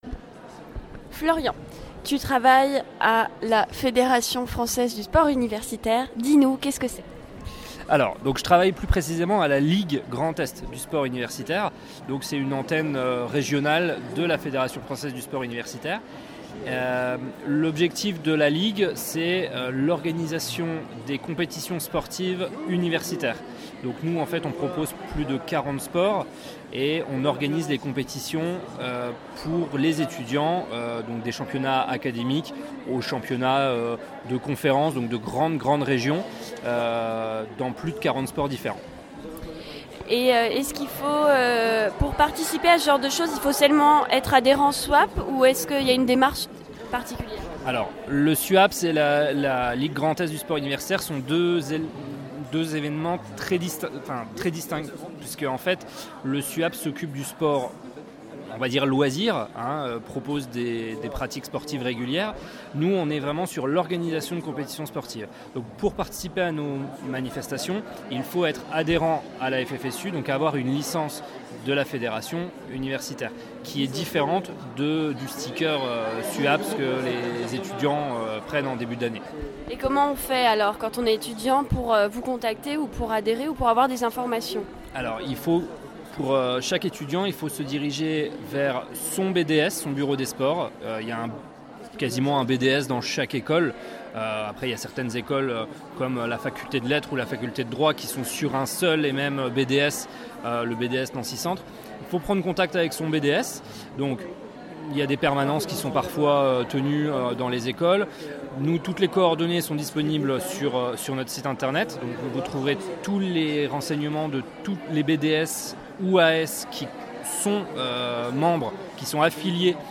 Les officiels de la vie étudiantes (transports, logements, restauration, sécu, job, etc.) et quelques associations nancéiennes se sont réunies pour un salon à l’Hôtel de ville de Nancy le samedi 7 juillet 2018.